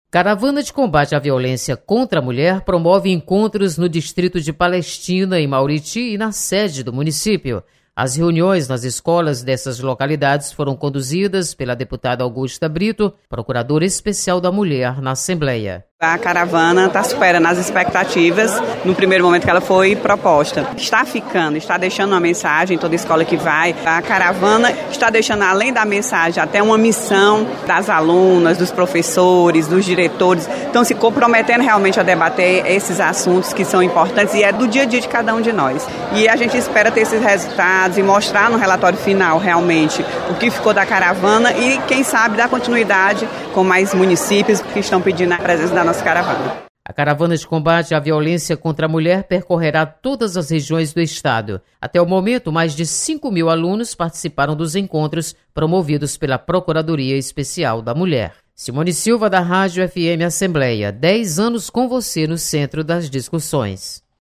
Caravana em defesa da mulher é recebida em Mauriti. Repórter